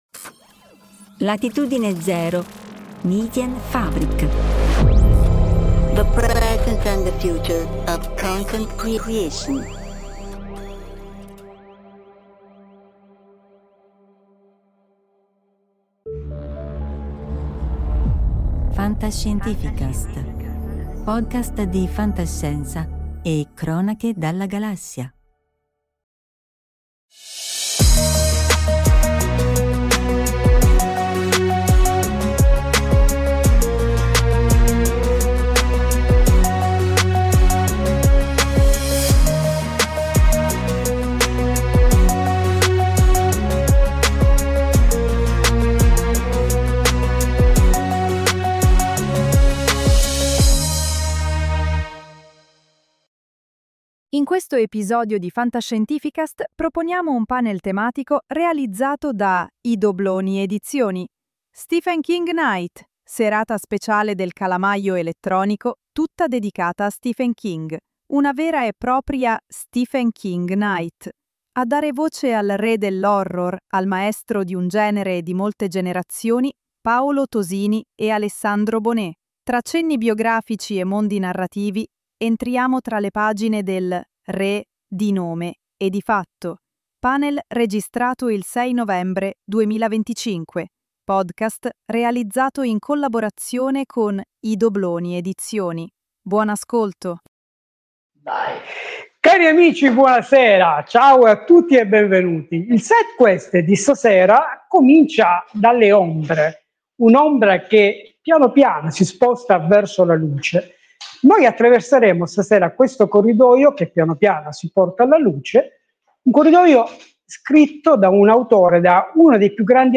Serata speciale del Calamaio Elettronico tutta dedicata a Stephen King. Tra cenni biografici e mondi narrativi, entriamo tra le pagine del Re… di nome e di fatto.